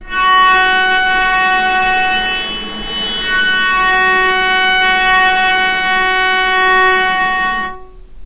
healing-soundscapes/Sound Banks/HSS_OP_Pack/Strings/cello/sul-ponticello/vc_sp-F#4-mf.AIF at bf8b0d83acd083cad68aa8590bc4568aa0baec05
vc_sp-F#4-mf.AIF